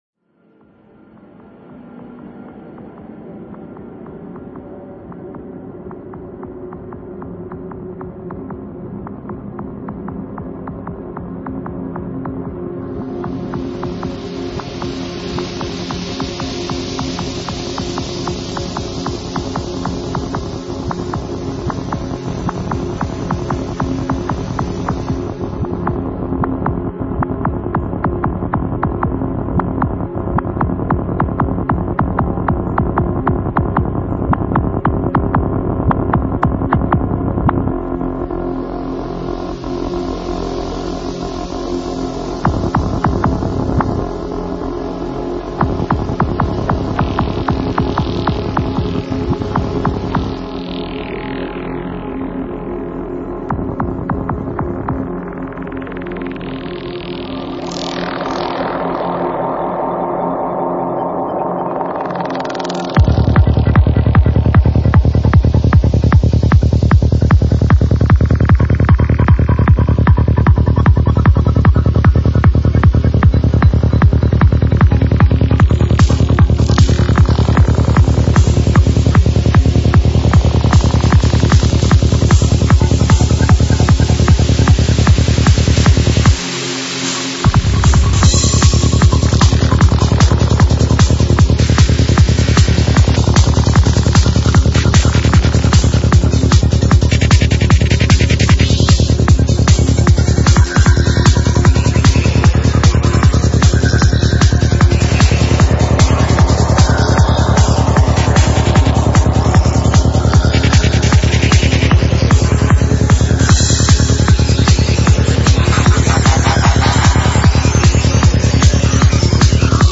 Стиль: Dark Psytrance